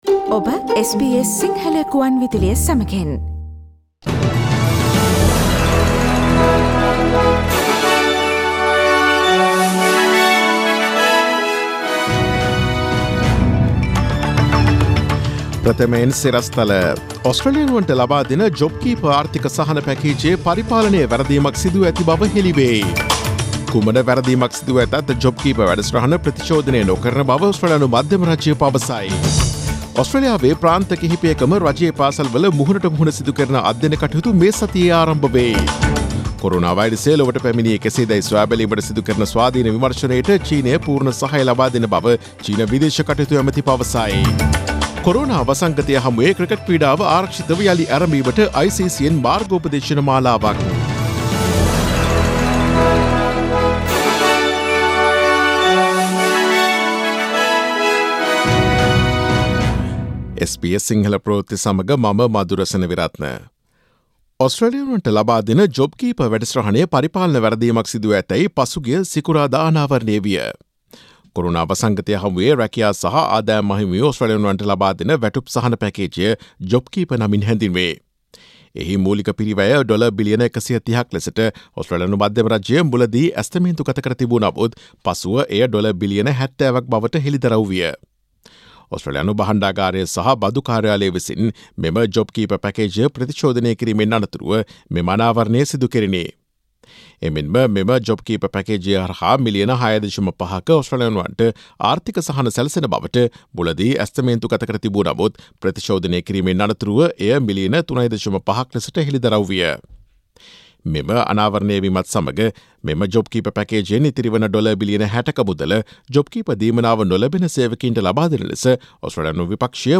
Daily News bulletin of SBS Sinhala Service: Monday 25 May 2020